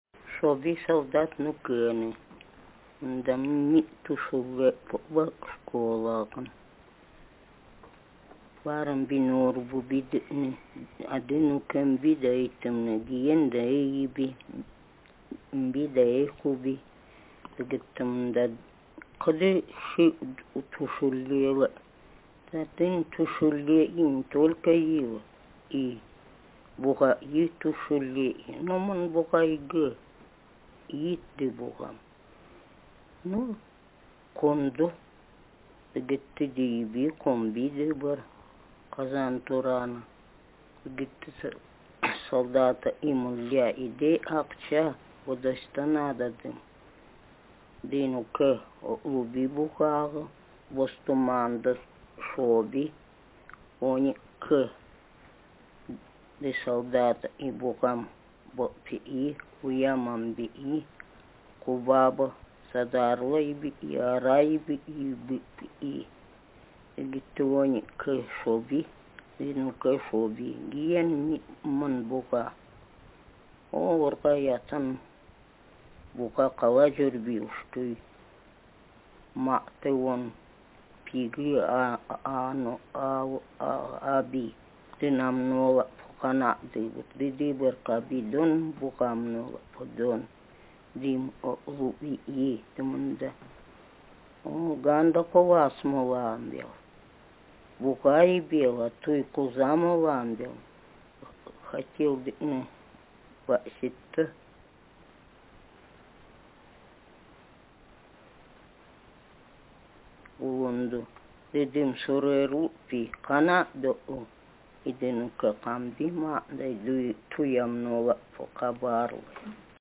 Speaker sexf
Text genretraditional narrative